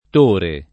Tore [ t 1 re ] pers. m. (= Salvatore)